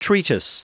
Prononciation du mot treatise en anglais (fichier audio)
Prononciation du mot : treatise